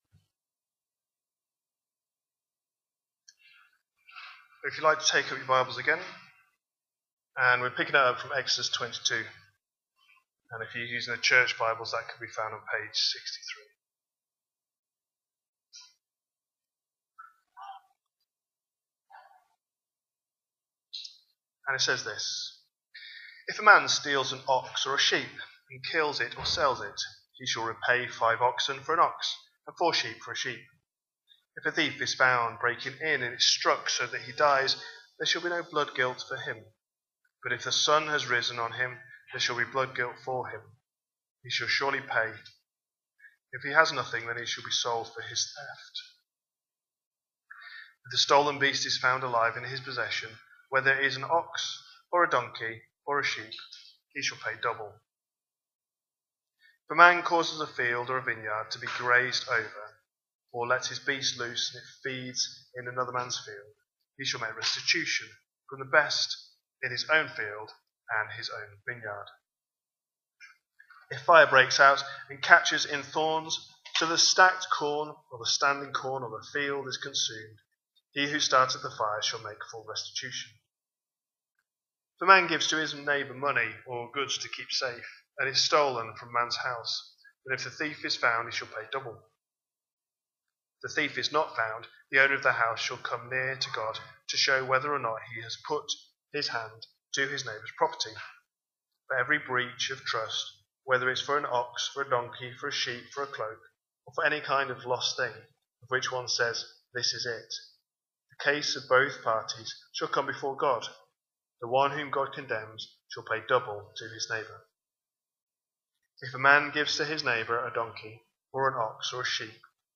A sermon preached on 31st August, 2025, as part of our Exodus series.